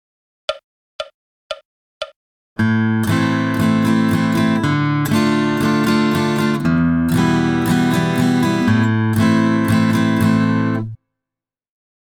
• Inclusief audio demo's voor elk nummer.